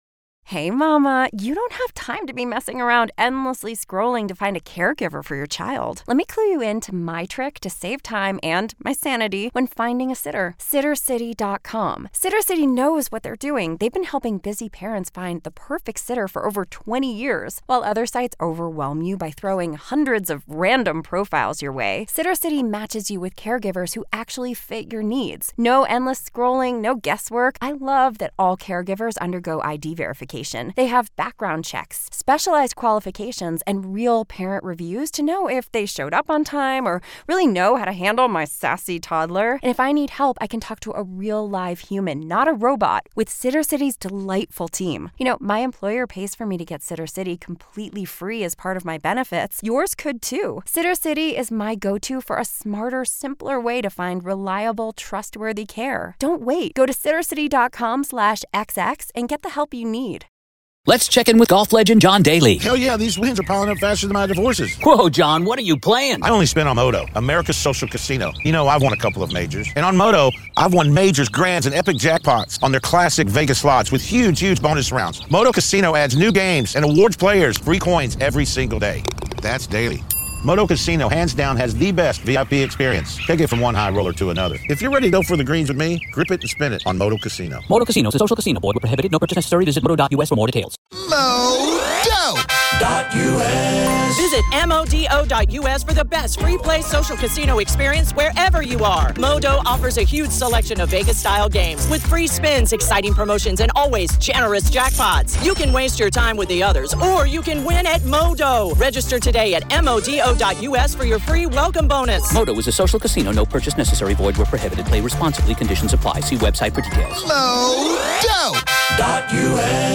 COURT AUDIO: MA v. Karen Read Murder Retrial - Motions Hearing Day 3 PART 2